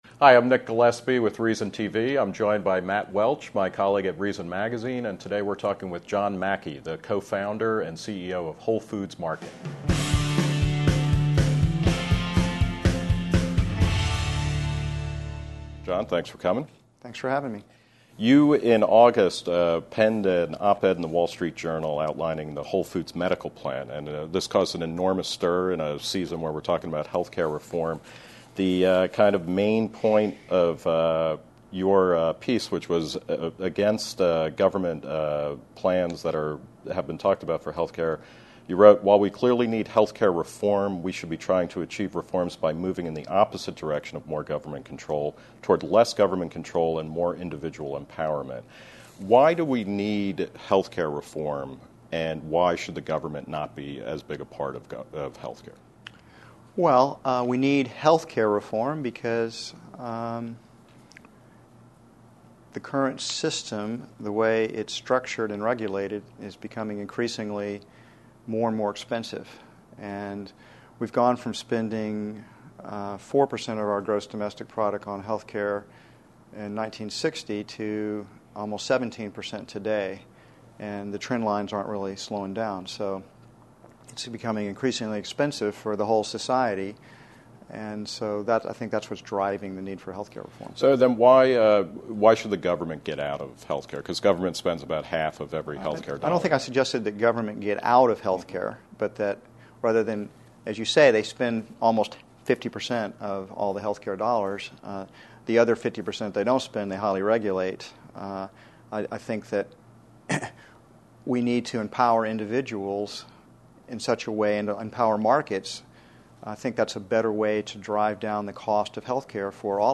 Note: This is the full version of an hour-long conversation with Mackey.